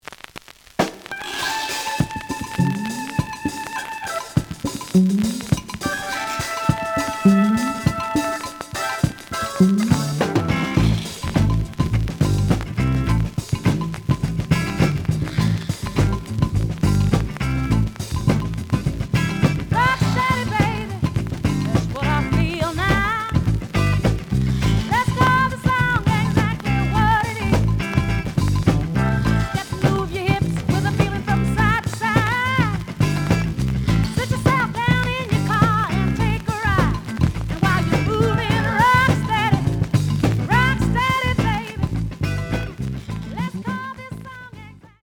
The audio sample is recorded from the actual item.
●Genre: Funk, 70's Funk
Some noise on both sides.